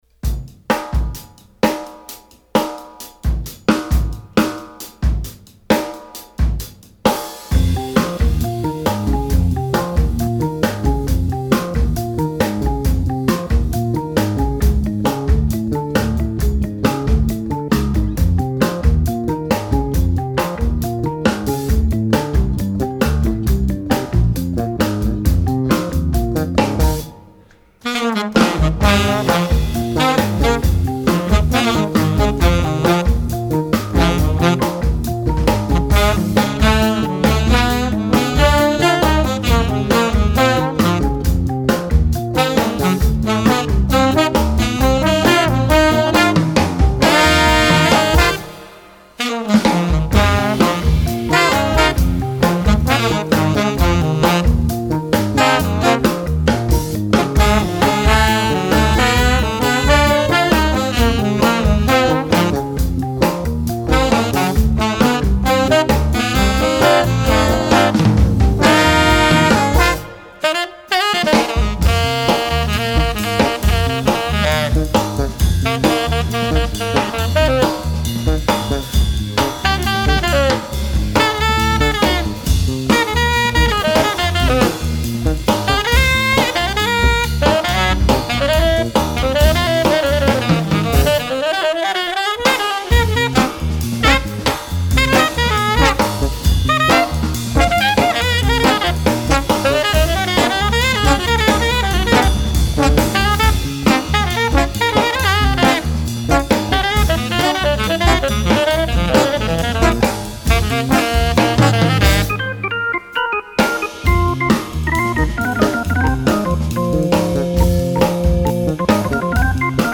Voicing: Combo Sextet